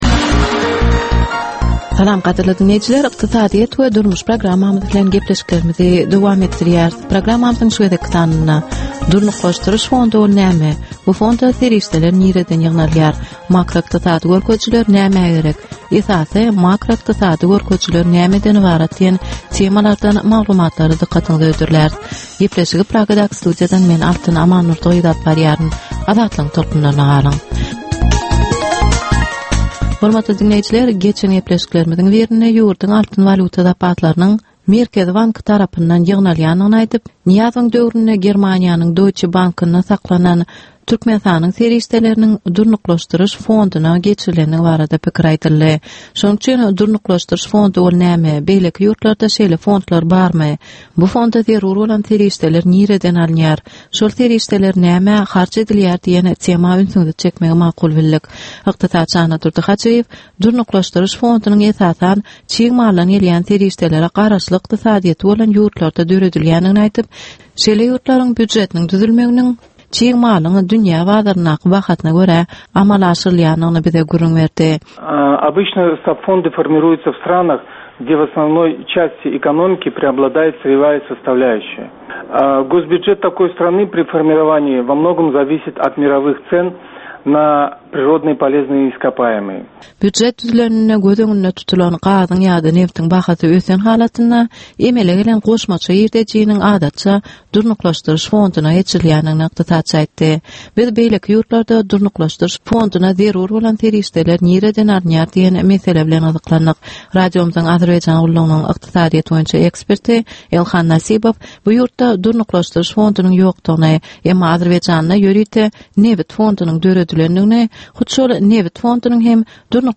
Türkmenistanyn ykdysadyýeti bilen baglanysykly möhüm meselelere bagyslanylyp taýýarlanylýan 10 minutlyk ýörite geplesik. Bu geplesikde Türkmenistanyn ykdysadyýeti bilen baglanysykly, seýle hem dasary ýurtlaryñ tejribeleri bilen baglanysykly derwaýys meseleler boýnça dürli maglumatlar, synlar, adaty dinleýjilerin, synçylaryn we bilermenlerin pikirleri, teklipleri berilýär.